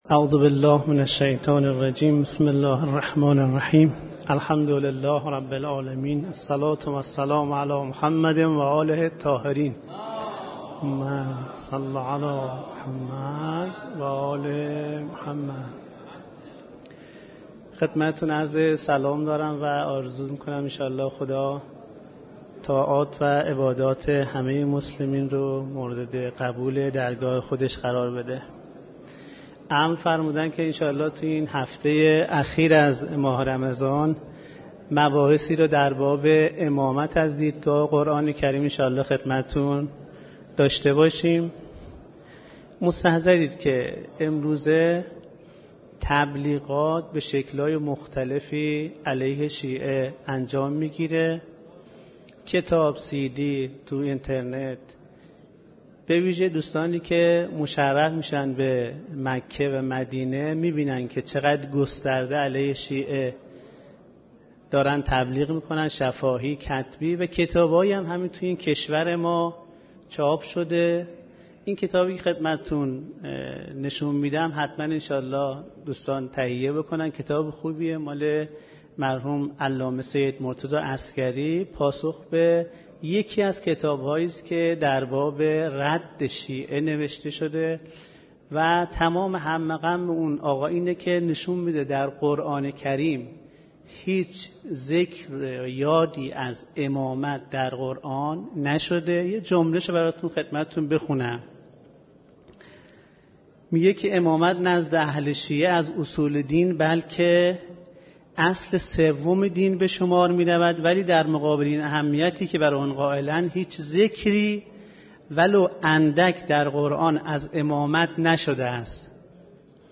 سخنرانيهاي ماه مبارک رمضان